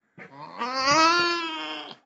Nutria-Geraeusche-Wildtiere-in-Europa.wav